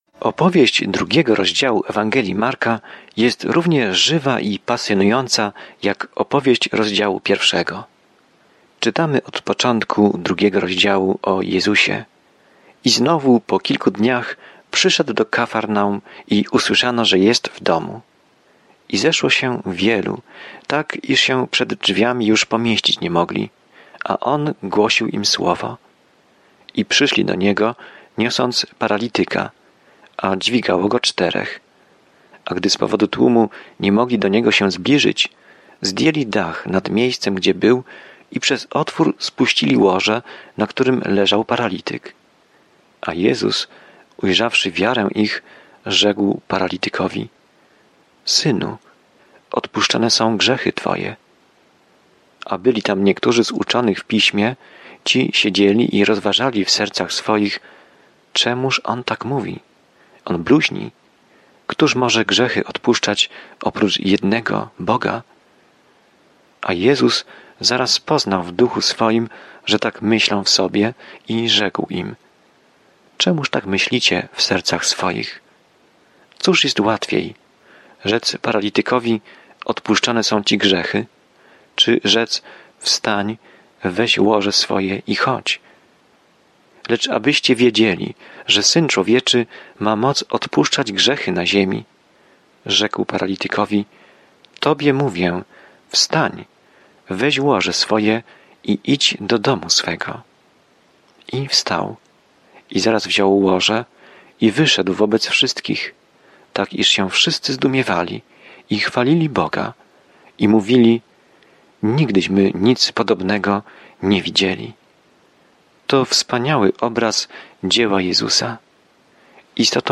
Pismo Święte Marka 2 Dzień 2 Rozpocznij ten plan Dzień 4 O tym planie Krótsza Ewangelia Marka opisuje ziemską służbę Jezusa Chrystusa jako cierpiącego Sługi i Syna Człowieczego. Codziennie podróżuj przez Marka, słuchając studium audio i czytając wybrane wersety ze słowa Bożego.